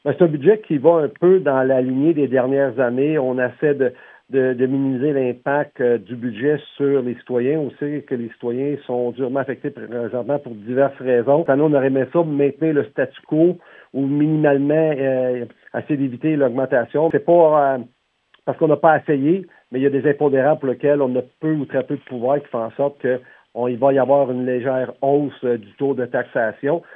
Paul Sarrazin, maire de la municipalité, développe sur cette hausse de budget en la catégorisant d’inévitable.